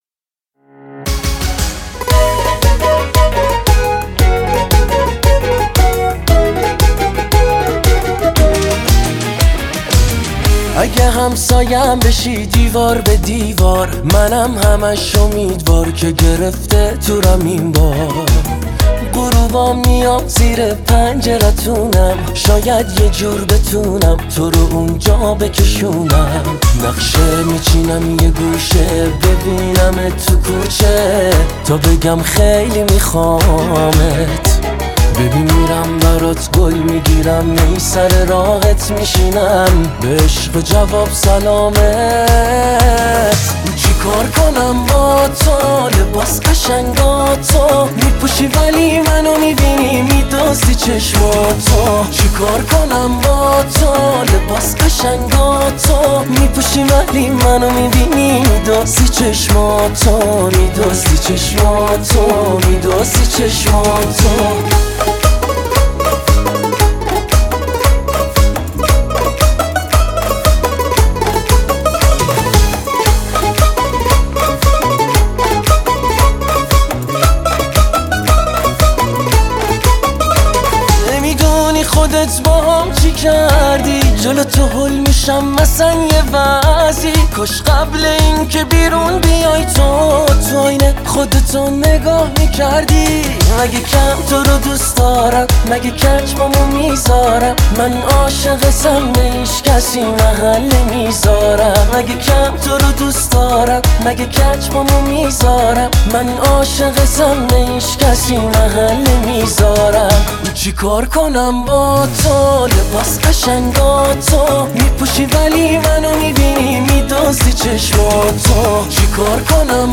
دانلود اهنگ جدید و شاد و خیلی خوب